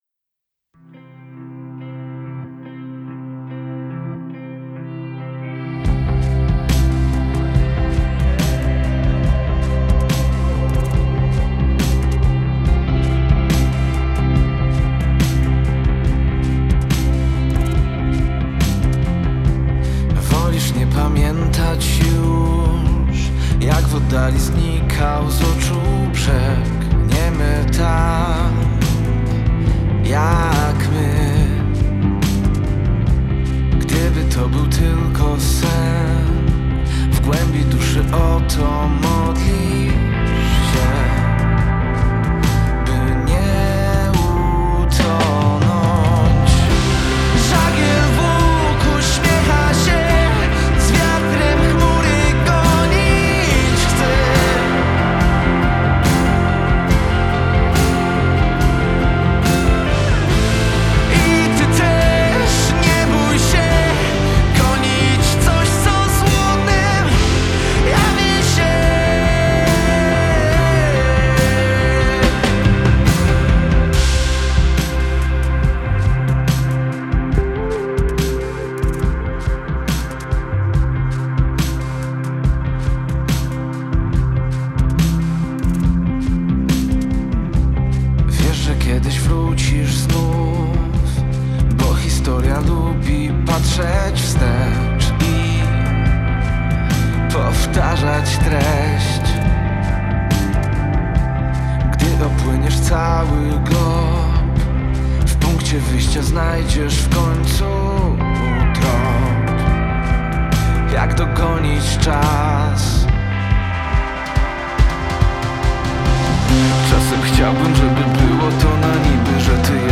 trochę country, trochę popu dużo klasy chill